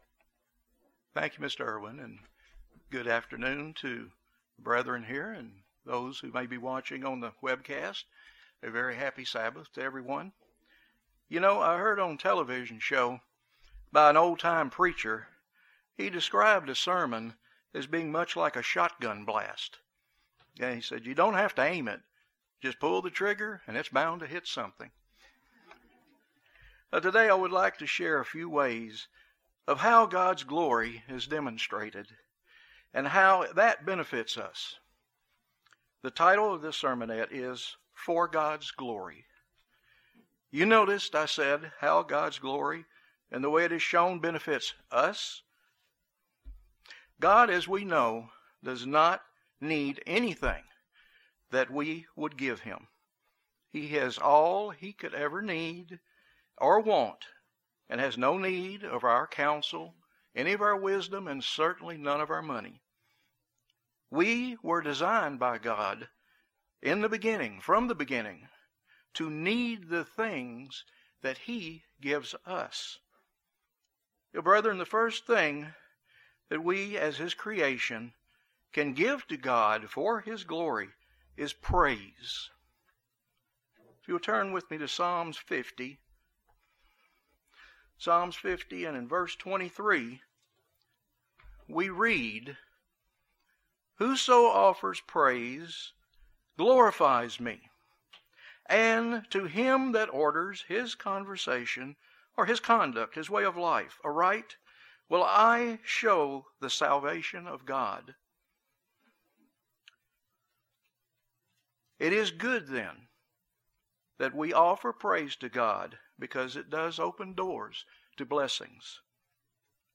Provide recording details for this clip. This message shows ways in which God's glory is demonstrated and how that benefits us. The recited poem is shared with written expressed permission from the author.